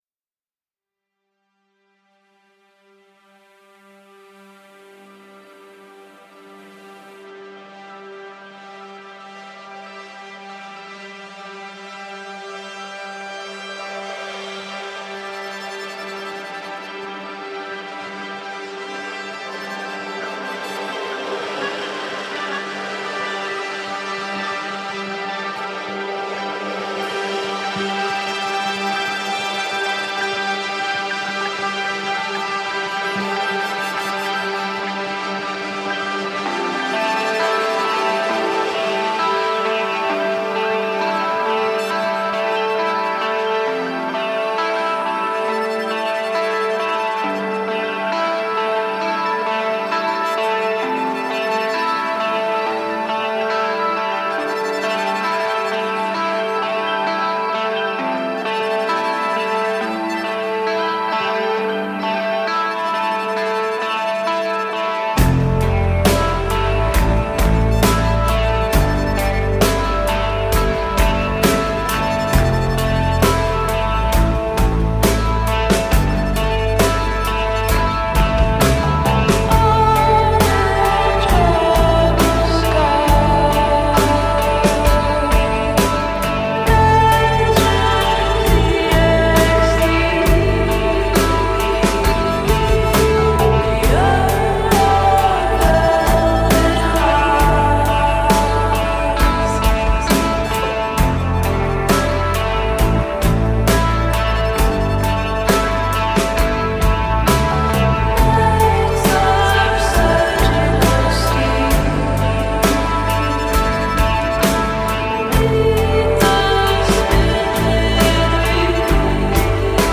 Alt-rockers